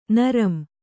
♪ naram